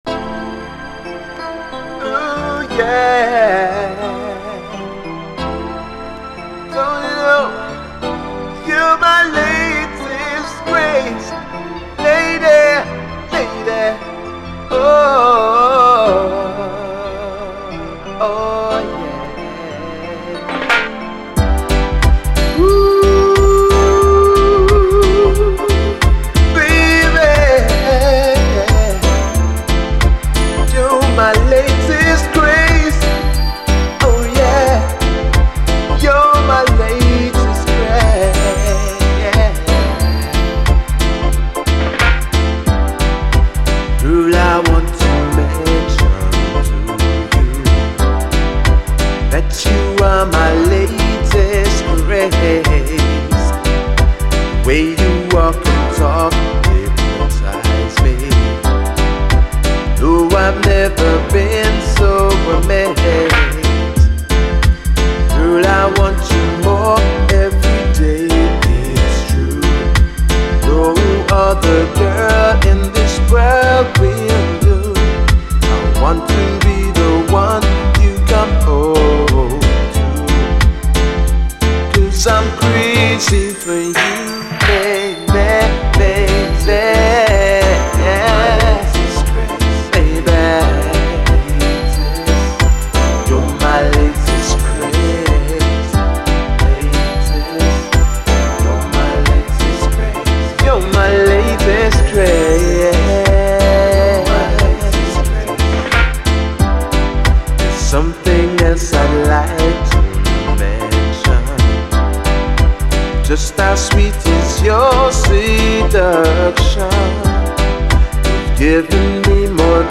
REGGAE, 7INCH
両面スムース＆メロウなグレイトUKラヴァーズ！